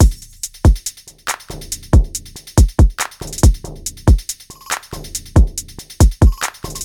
Category 🎵 Music
beat beats drumkit fast Gabber hardcore House Jungle sound effect free sound royalty free Music